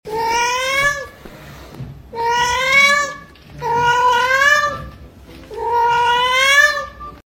Cat Language (Part 16) 😿 sound effects free download